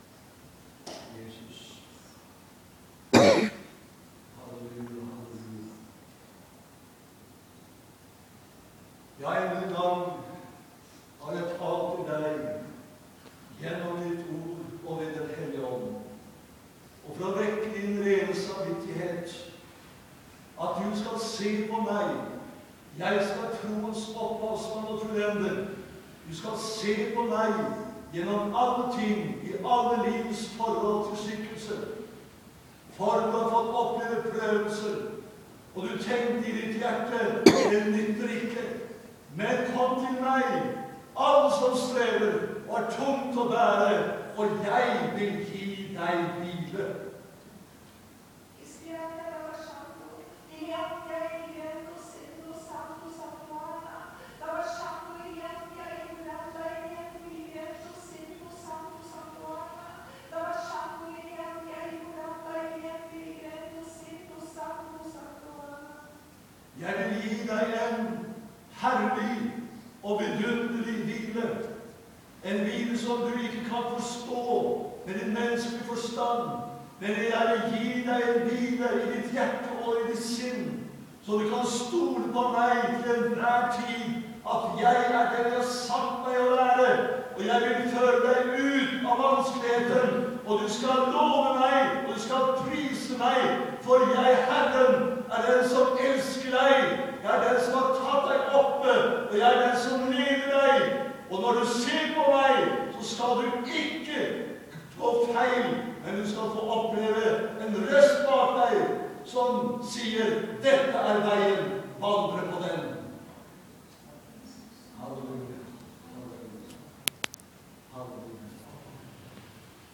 Kveike nådegåva, vere brennande i ånda. Møte i Maranatha søndag 14.4.2013.
Tale.
Tungetale